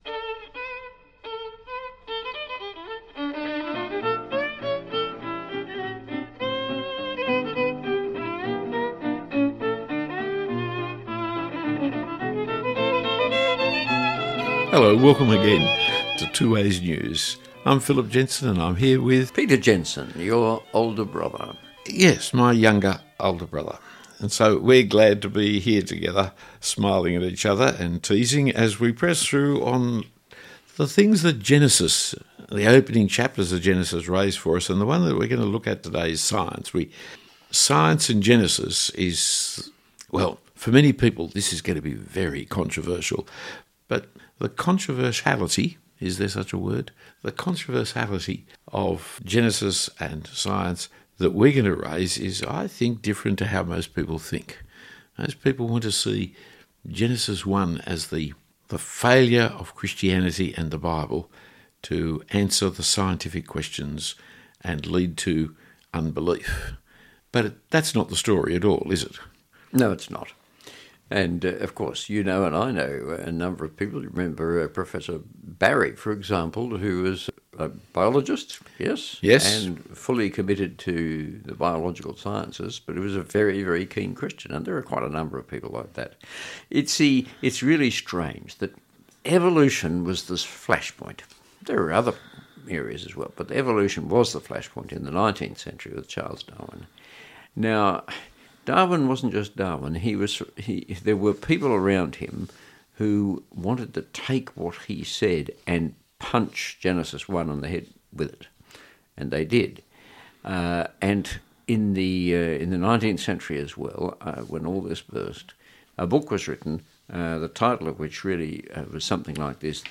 This discussion explores the background to the warfare between science and religion (aka Christianity).